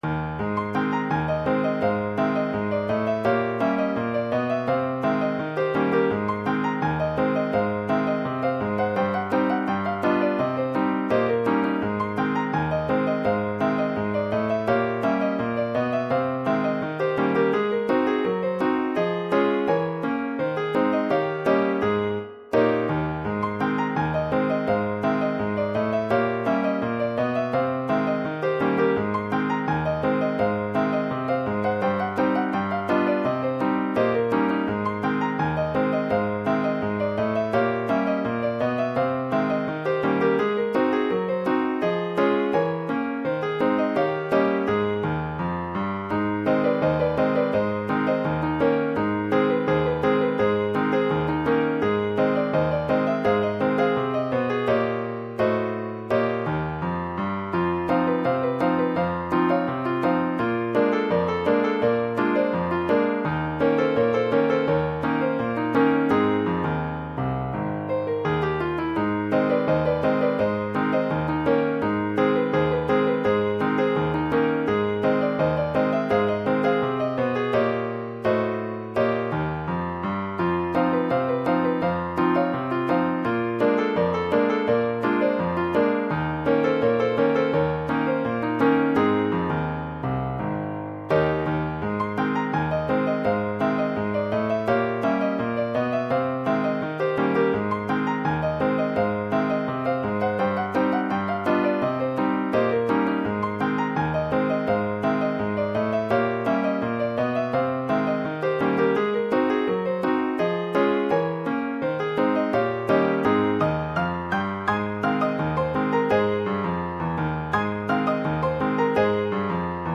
This is not a performance version, but is simply a file which lilypond produces when processing the source.
An mpeg (.mp3) file produced from the midi file.